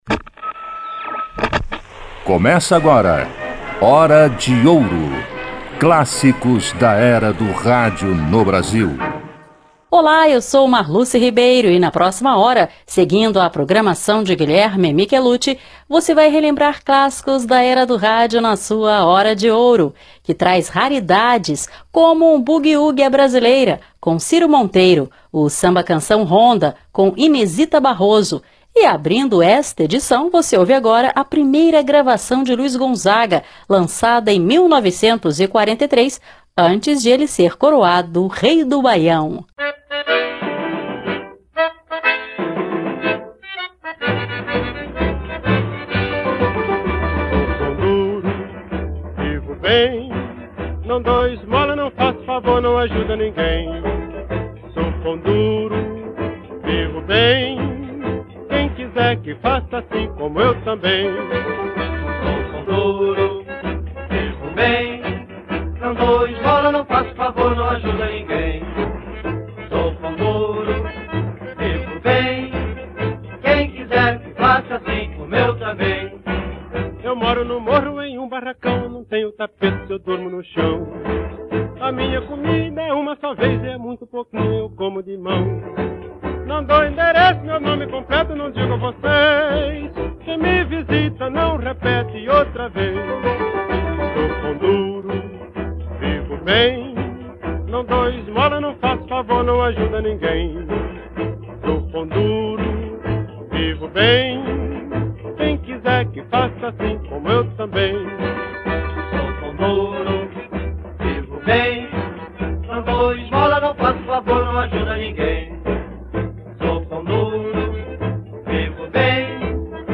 Ainda ouviremos um boogie-woogie à brasileira
samba-canção
Samba